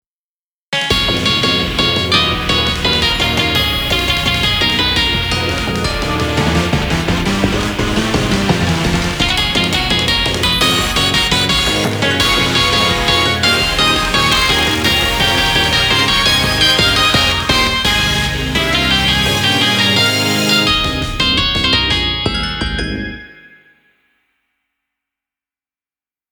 ダークが色濃いシリーズです。